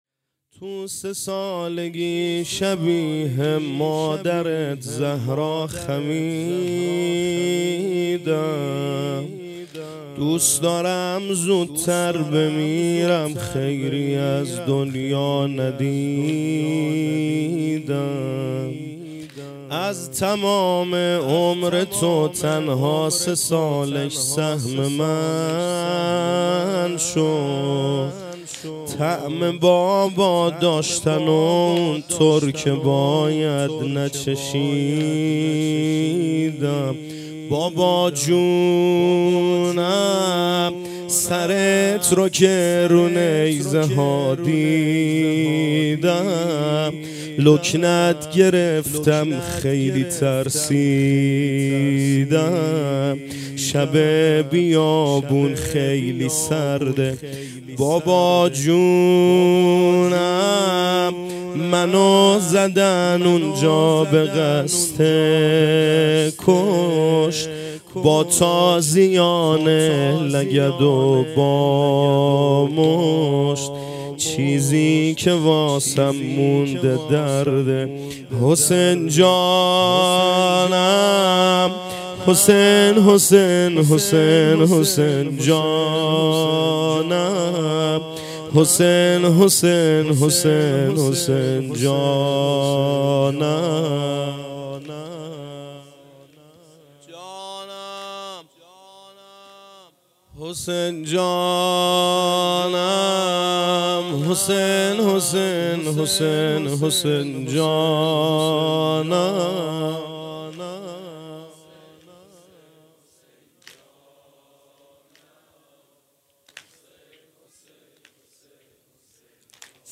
محرم 1402 - شب سوّم